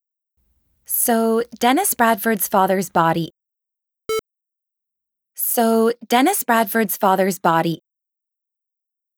Gain vs. Mouthnoises
Forum Sample- Higher Gain.wav (1.39 MiB) Downloaded